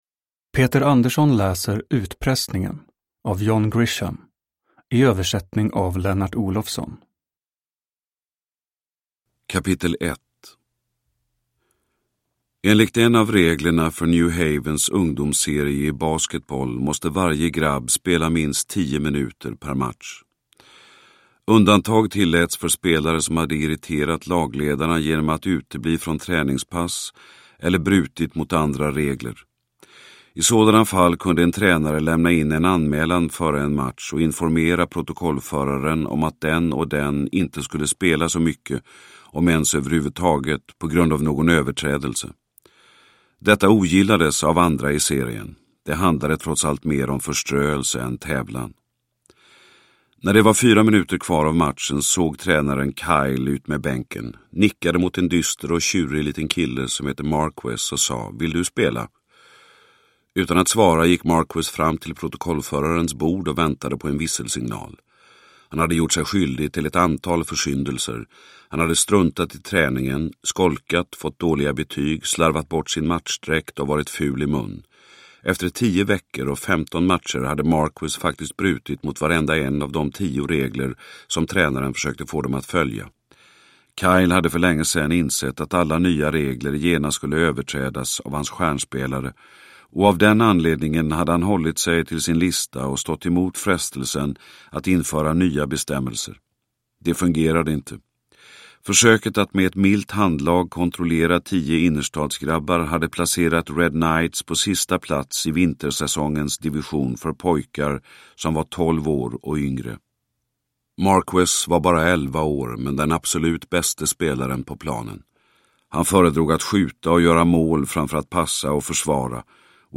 Utpressningen (ljudbok) av John Grisham